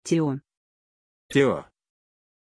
Pronuncia di Teo
pronunciation-teo-ru.mp3